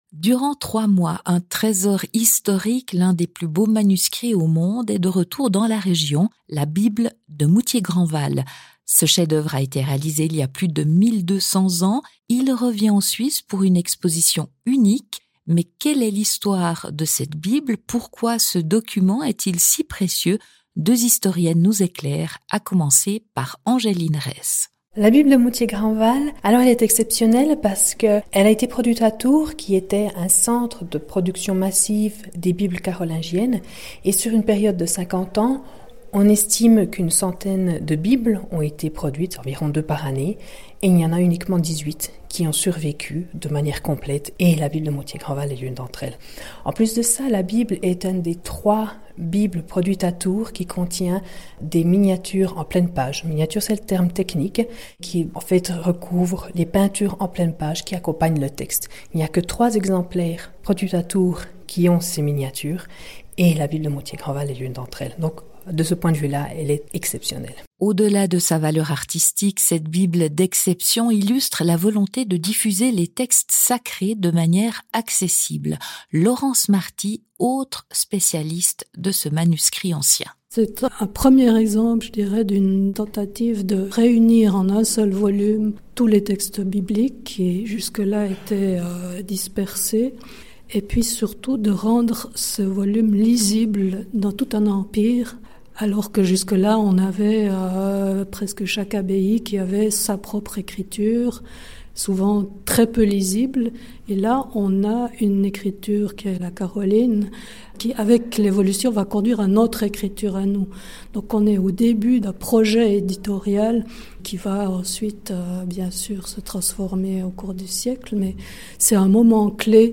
historienne et sociologue.